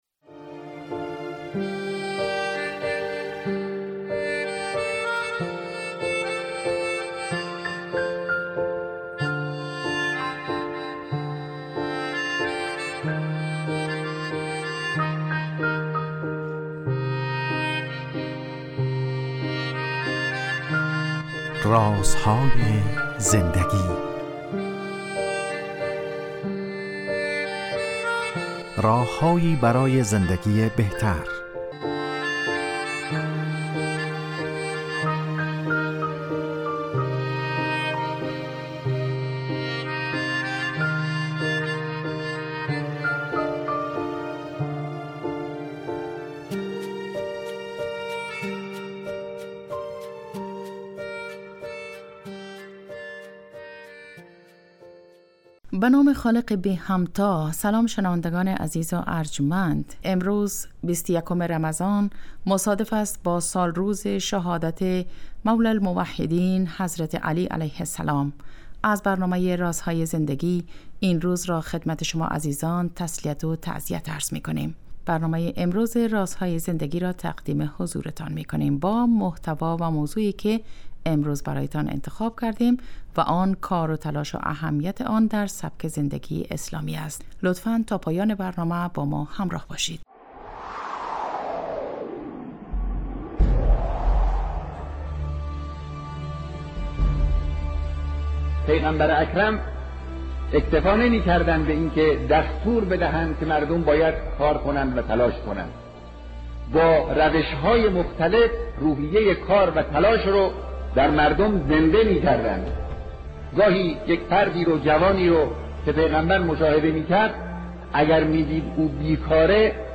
با مجموعه برنامه " رازهای زندگی" و در چارچوب نگاهی دینی به سبک زندگی با شما هستیم. این برنامه به مدت 15 دقیقه هر روز ساعت 11:35 به وقت افغانستان از رادیو دری پخش می شود .